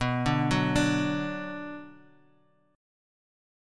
Bm chord